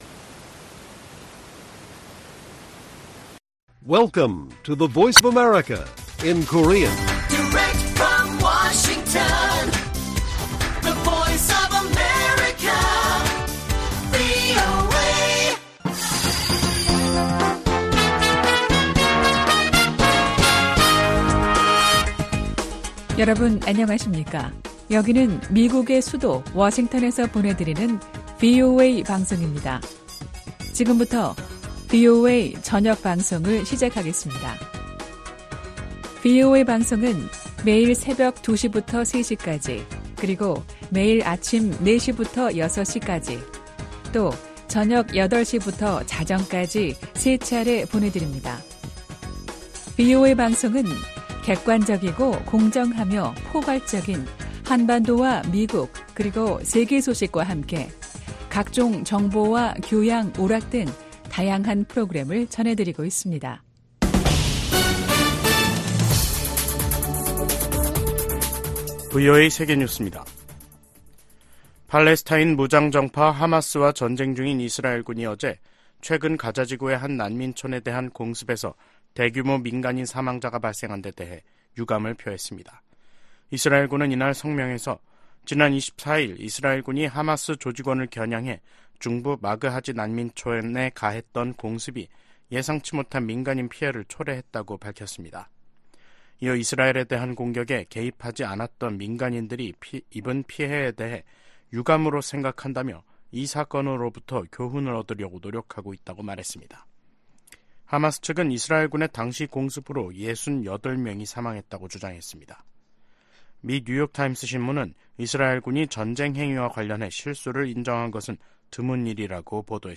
VOA 한국어 간판 뉴스 프로그램 '뉴스 투데이', 2023년 12월 29일 1부 방송입니다. 하마스가 북한의 유탄발사기 부품을 이용해 살상력이 큰 신무기를 만든 것으로 확인됐습니다. 미국 전문가들은 김정은 북한 국무위원장의 '전쟁준비 완성' 지시에, 핵 도발은 김씨 정권의 종말을 뜻한다고 경고했습니다. 미국 내 일각에선 북한 비핵화 대신 핵무기 감축 협상이 현실적인 방안이라는 주장이 나오고 있습니다.